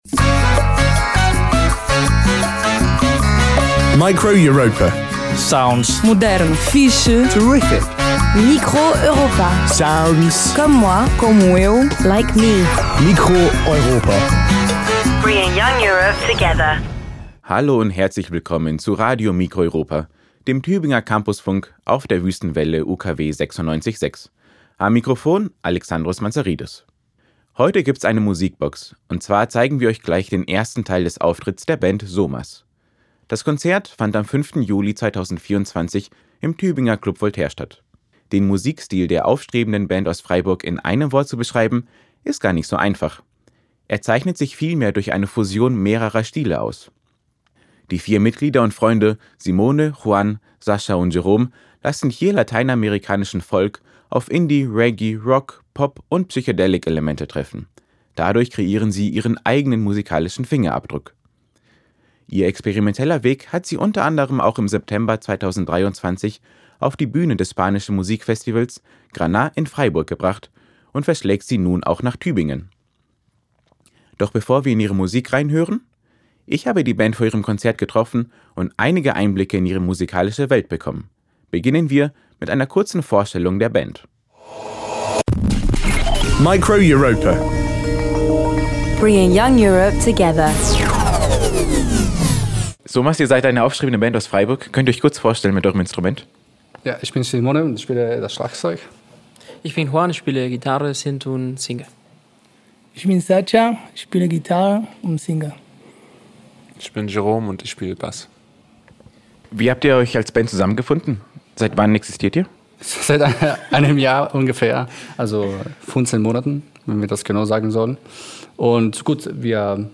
Er zeichnet sich eher durch eine Fusion mehrere Stile aus.
Drums
Gitarre, Gesang und Keybord
E-Gitarre, Gesang
Form: Live-Aufzeichnung, geschnitten